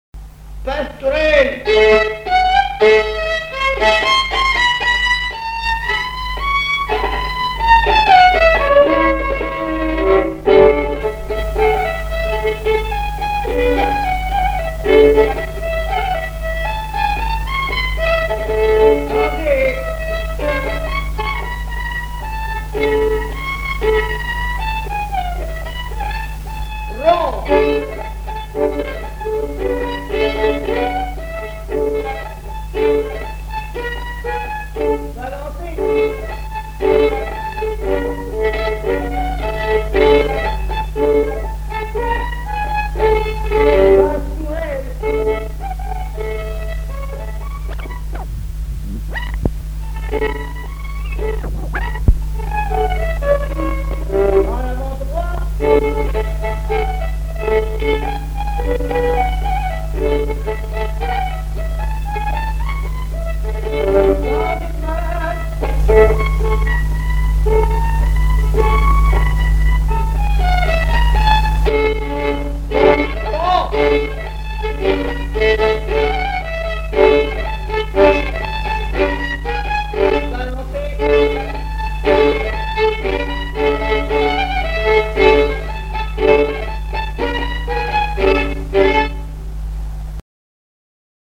Mémoires et Patrimoines vivants - RaddO est une base de données d'archives iconographiques et sonores.
danse : quadrille : pastourelle
Répertoire de violoneux
Pièce musicale inédite